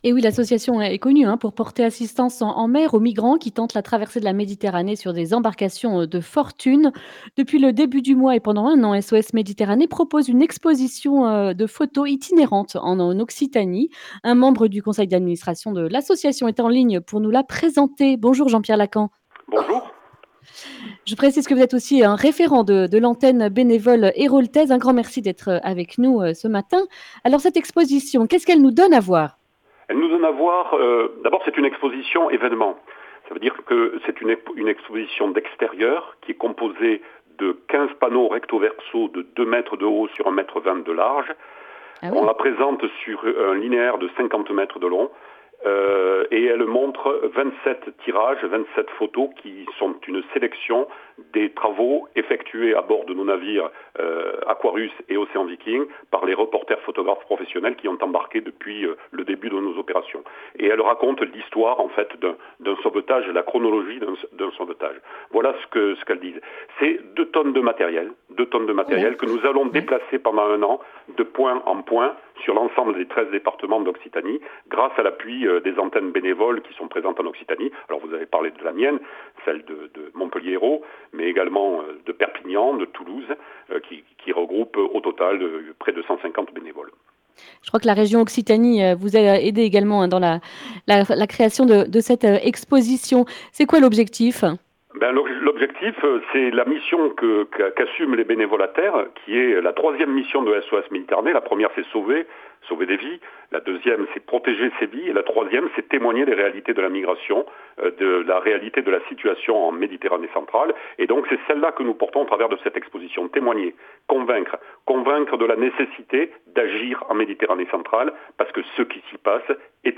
mercredi 10 mars 2021 Le grand entretien Durée 10 min